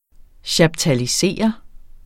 Udtale [ ɕɑbtaliˈseˀʌ ]